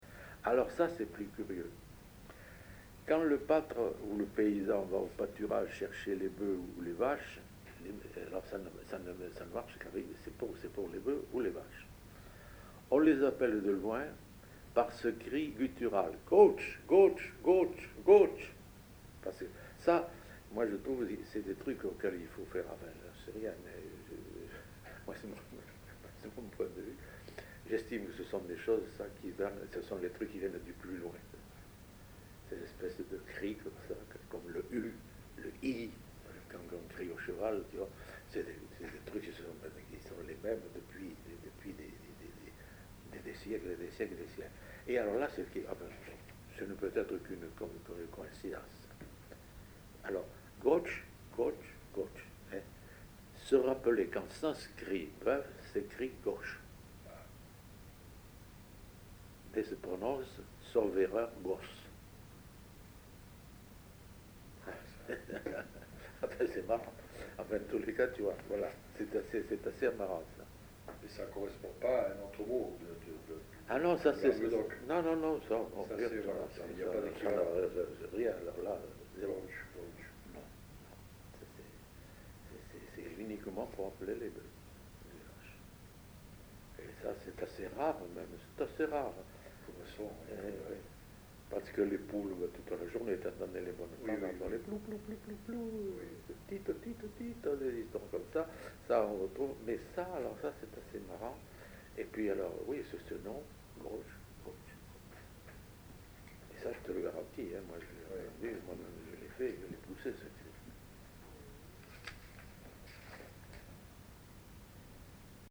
Appels au bétail
Aire culturelle : Rouergue
Lieu : Saint-Sauveur
Genre : expression vocale
Type de voix : voix d'homme
Production du son : crié
Classification : appel au bétail
Notes consultables : Contient plusieurs appels : boeufs, poules.